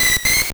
Cri de Rémoraid dans Pokémon Or et Argent.